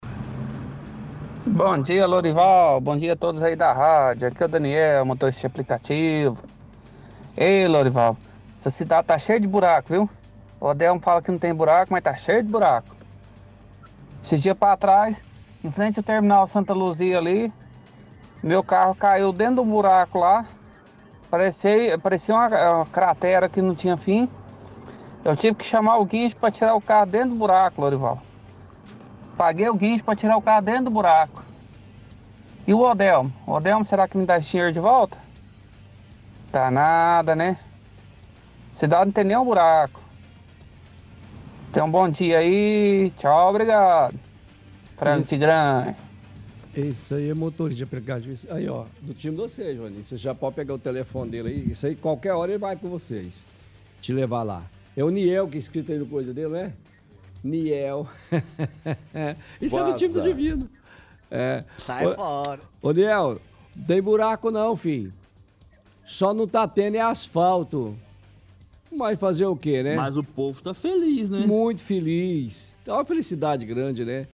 – Ouvinte reclama de buracos pela cidade, dizendo que precisou chamar guincho após ter caído em um.